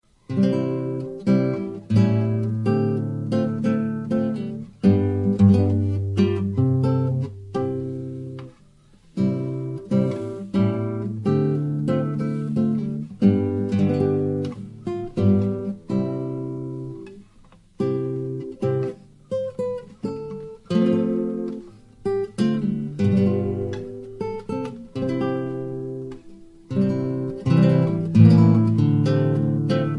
Traditional Polish Christmas Carols on classical guitar
(No Singing).